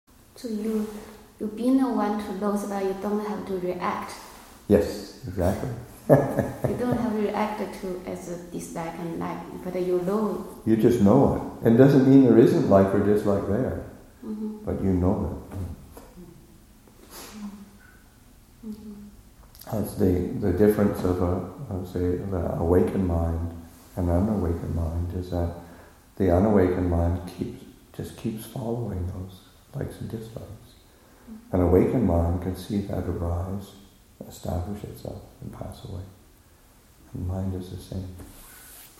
Q&A session, Nov. 18, 2014